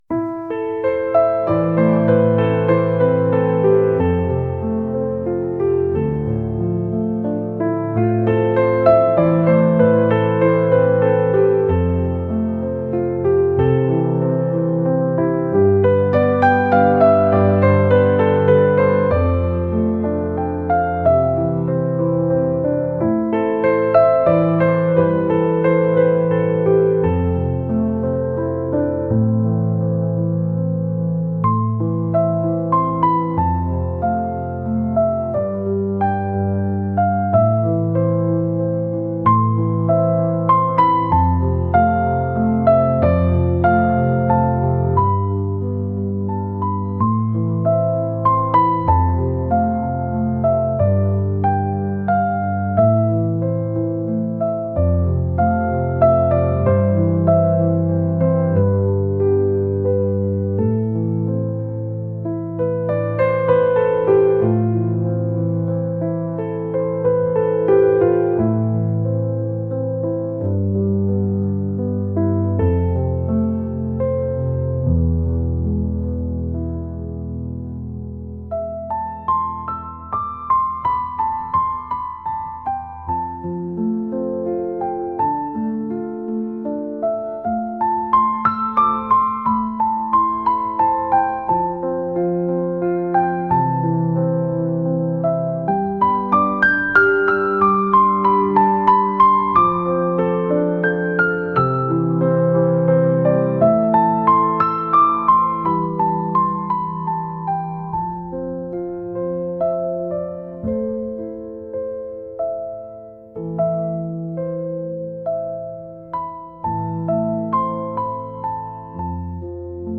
soulful | classical | ethereal